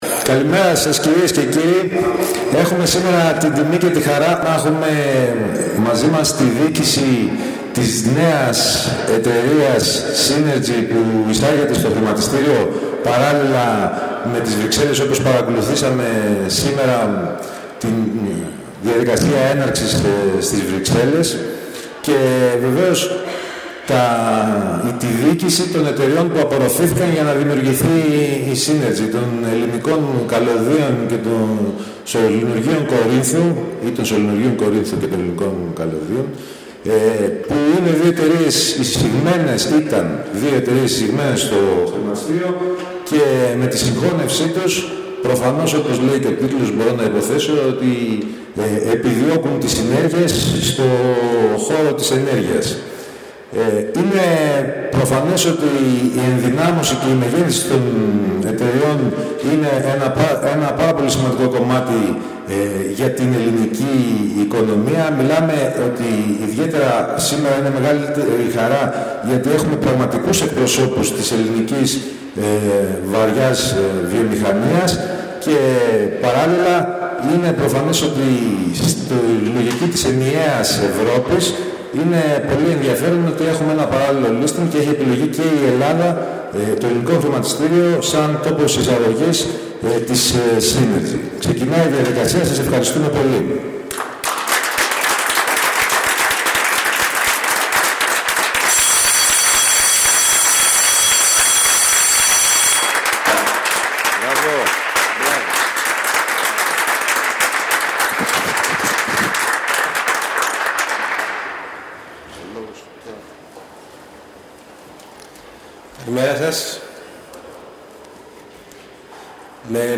Ηχητικό απόσπασμα της εκδήλωσης